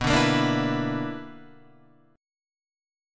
B Minor Major 7th Double Flat 5th